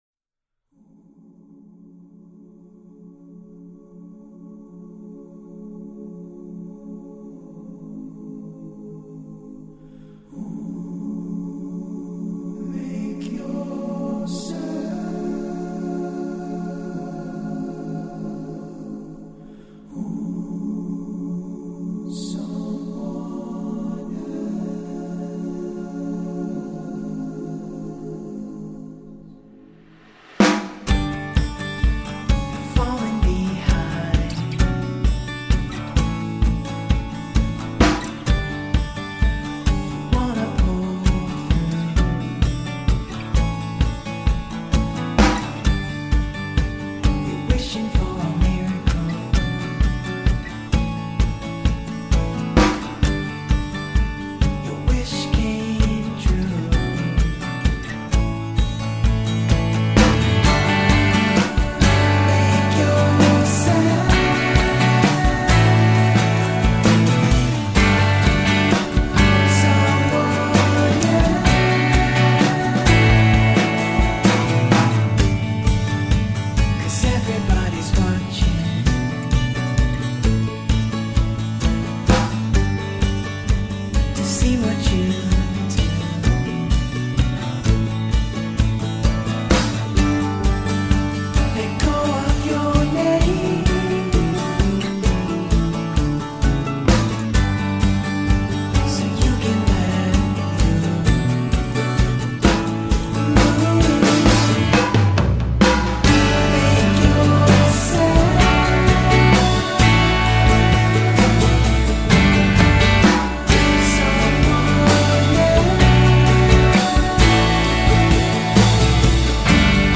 alternative indie-folk flavored